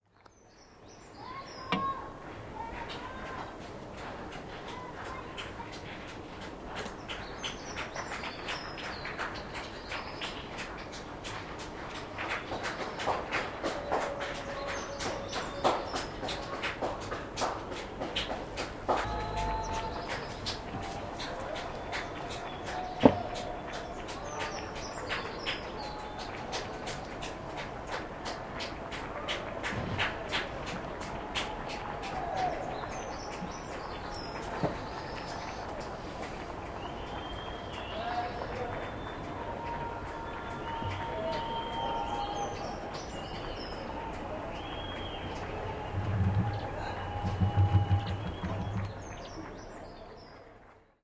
Prête moi tes yeux : Covid 19 Confinement 38 Applause